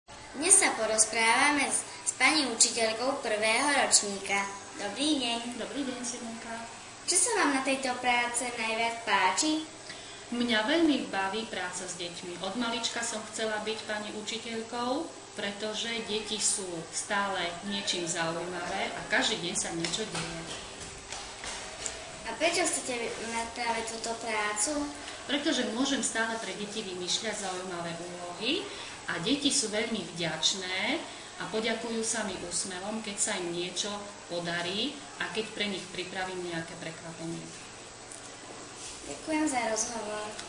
3 Rozhovor s prvackou p. ucitelkou.MP3